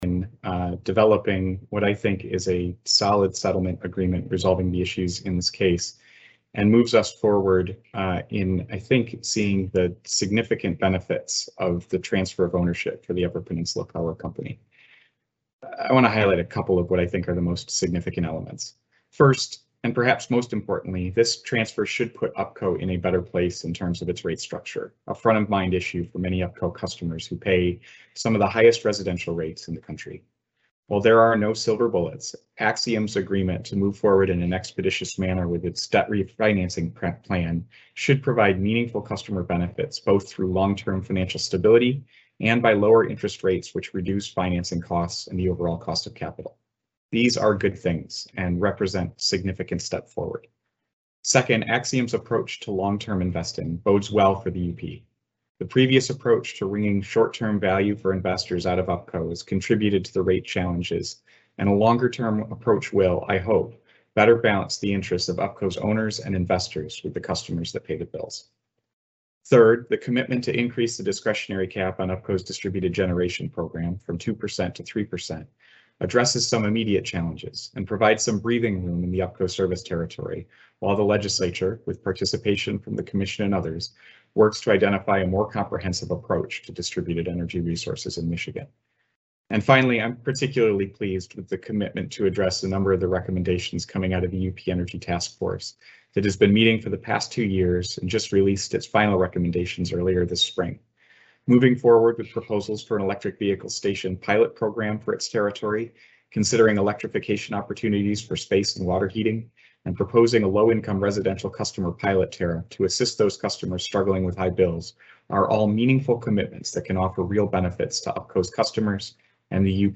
CLICK TO HEAR COMMENTS FROM MPSC CHAIRMAN DAN SCRIPPS